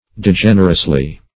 degenerously - definition of degenerously - synonyms, pronunciation, spelling from Free Dictionary Search Result for " degenerously" : The Collaborative International Dictionary of English v.0.48: Degenerously \De*gen"er*ous*ly\, adv.
degenerously.mp3